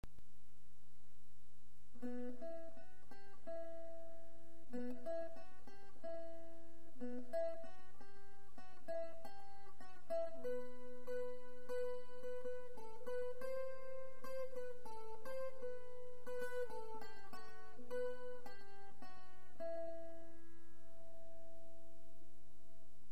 Traditionnels